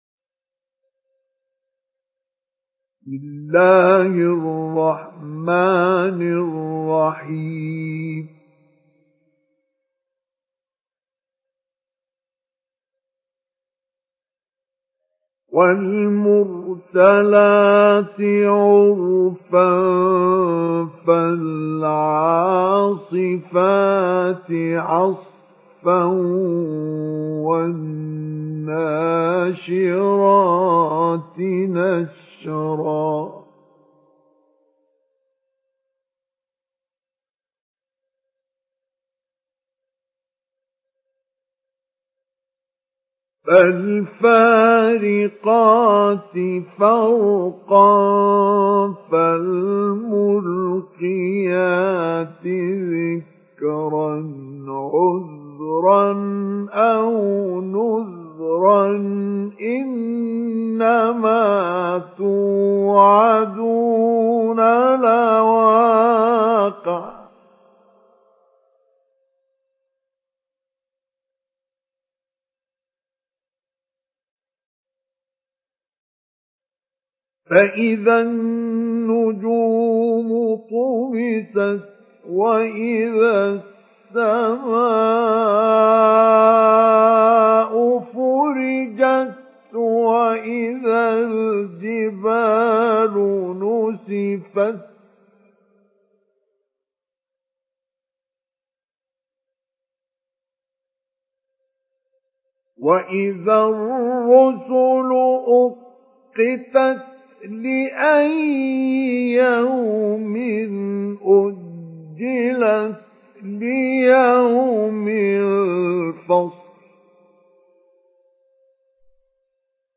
Surah Mürselat MP3 by Mustafa Ismail Mujawwad in Hafs An Asim narration.